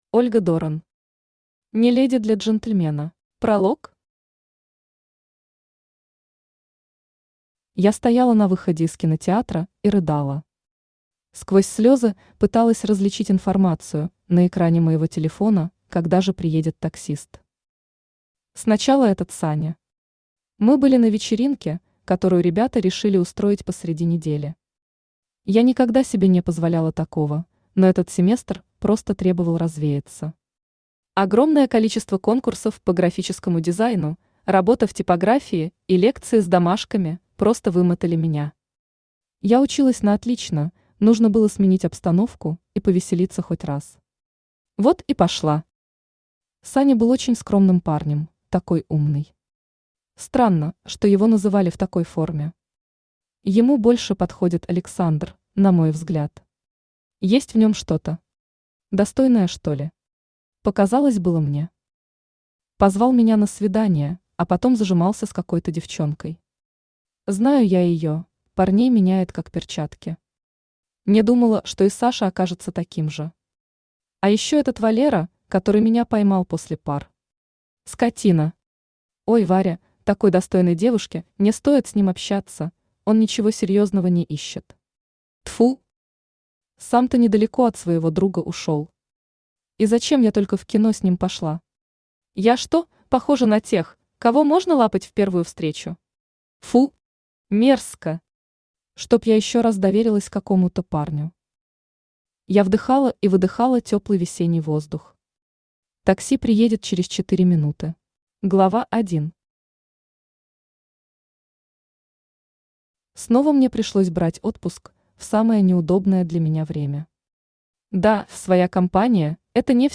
Аудиокнига Не леди для Джентльмена | Библиотека аудиокниг
Aудиокнига Не леди для Джентльмена Автор Ольга Дорен Читает аудиокнигу Авточтец ЛитРес.